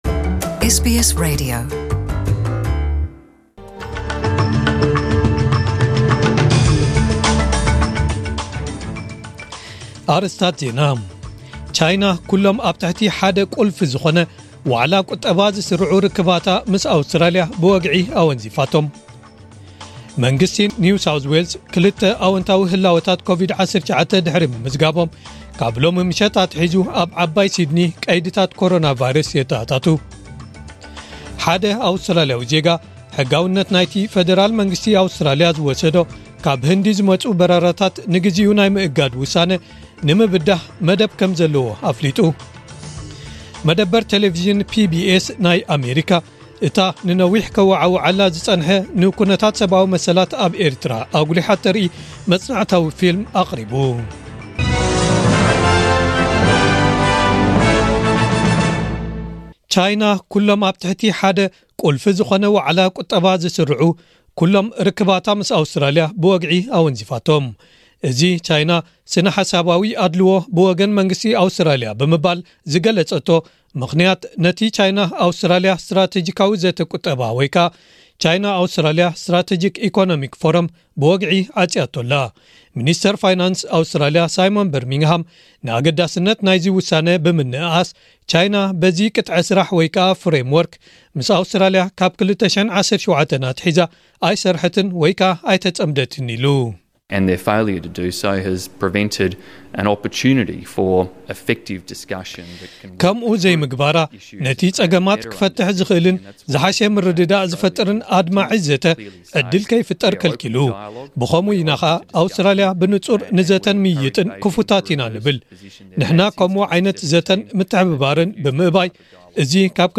ዕለታዊ ዜና ኤስቢኤስ ትግርኛ (06/05/2021)